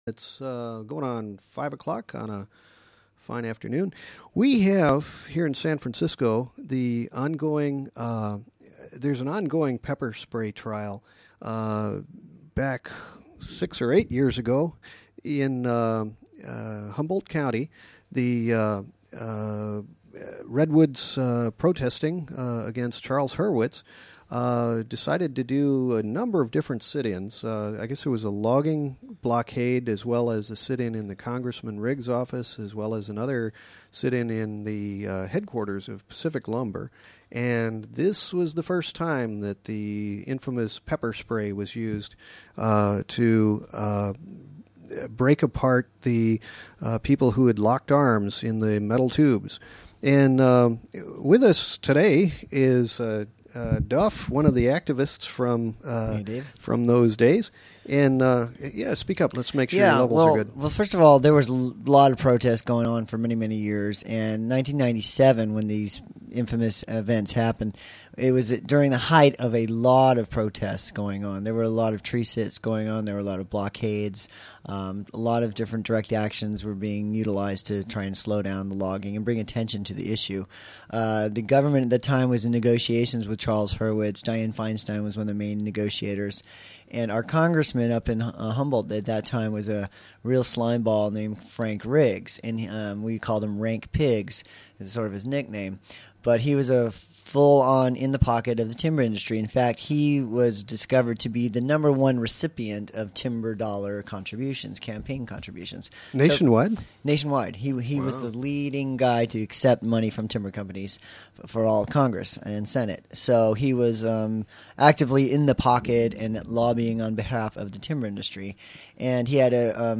Pepper Spray Q-Tip Swabbing Case interview